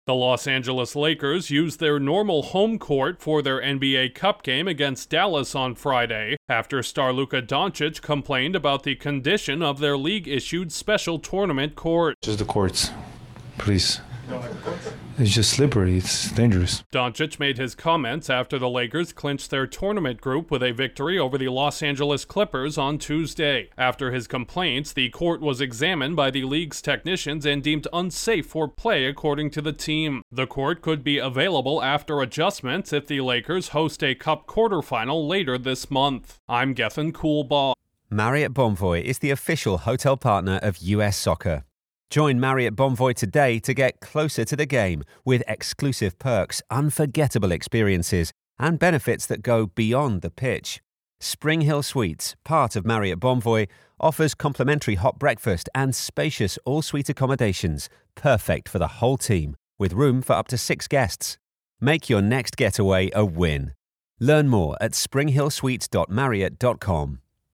An NBA star is speaking out against unsafe playing conditions involving a tournament court in Los Angeles. Correspondent